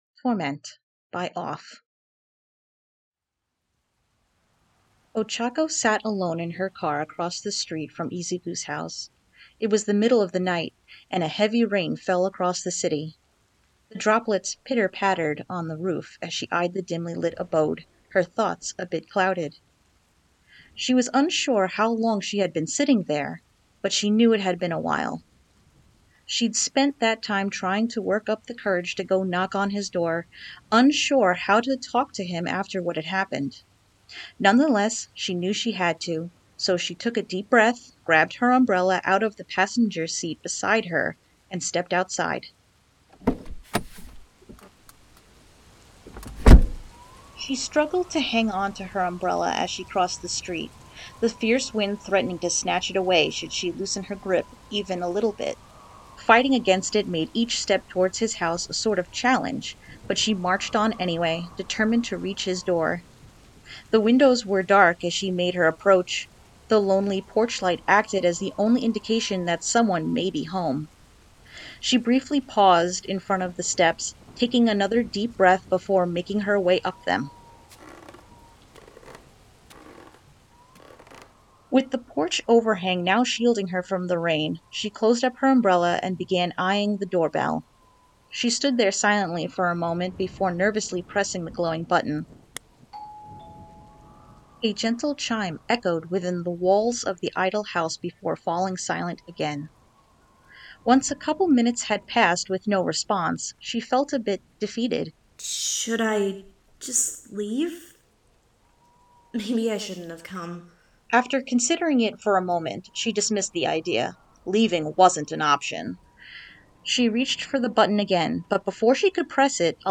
Torment | Podfic
Voice of Izuku Midoriya
Voice of Ochako Uraraka
Doorbell A
Door Open And Close
Rain on Windows, Interior, A.wav